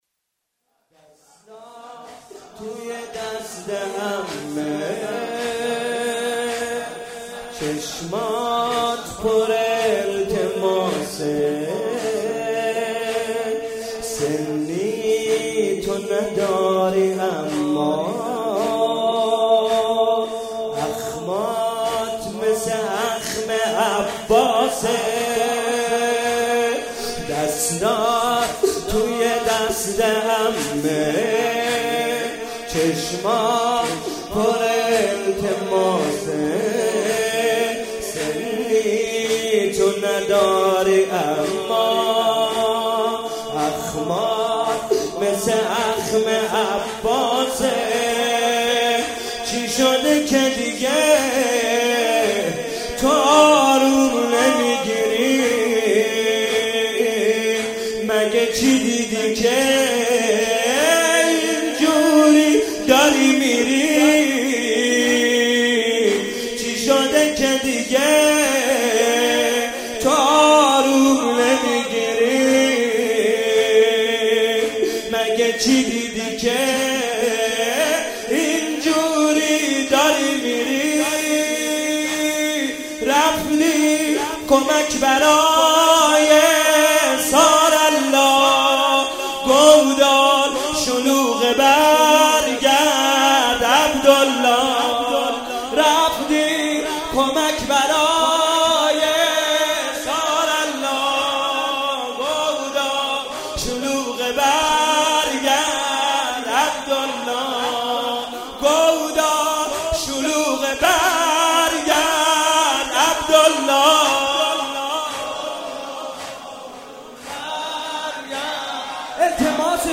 مناسبت : شب پنجم رمضان
قالب : زمینه